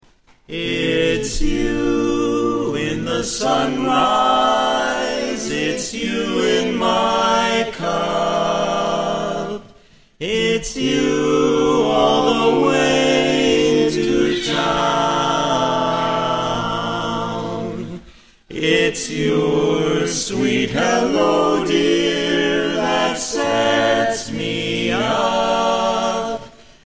Tags: Music Barbershop Quartet soundboard Barbershop Quartet Songs Music program